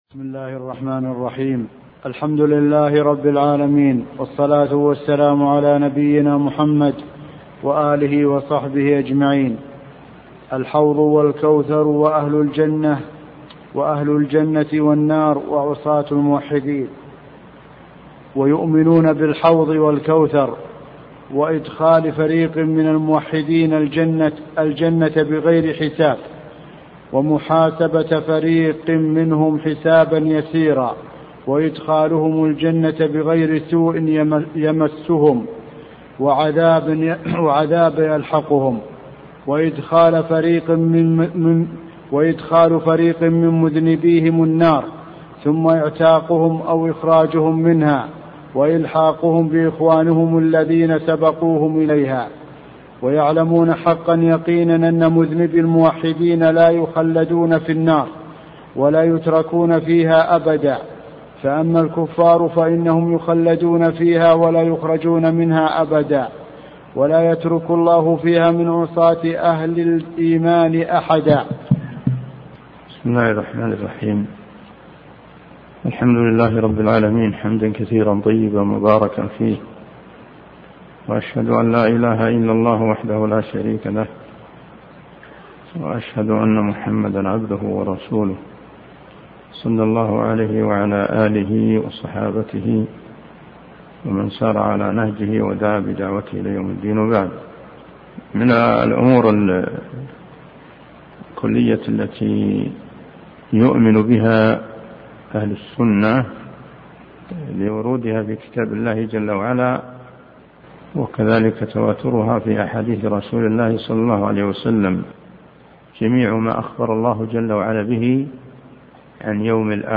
عنوان المادة الدرس (3) شرح عقيدة السلف أصحاب الحديث تاريخ التحميل الخميس 9 فبراير 2023 مـ حجم المادة 30.45 ميجا بايت عدد الزيارات 138 زيارة عدد مرات الحفظ 64 مرة إستماع المادة حفظ المادة اضف تعليقك أرسل لصديق